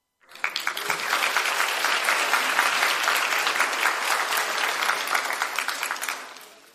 Sound Effects
Moderate Clapping